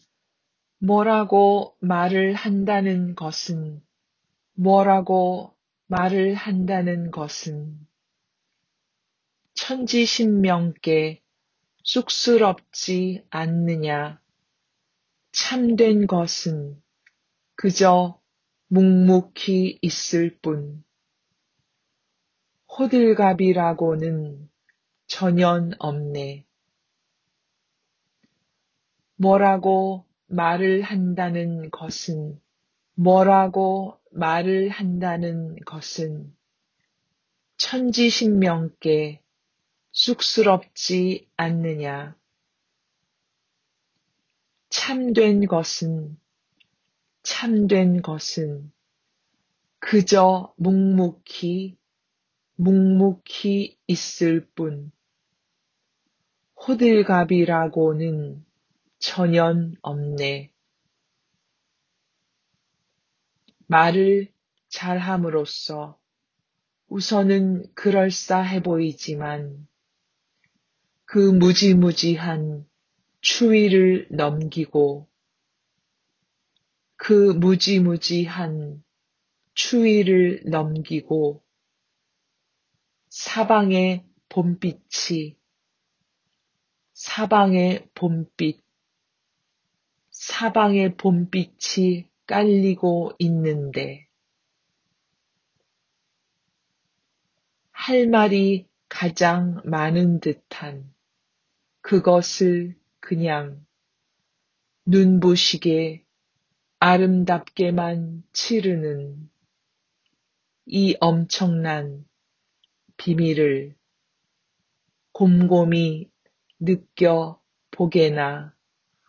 Pronunciation MP3 :
SBMP1883-PronunciationGuide.mp3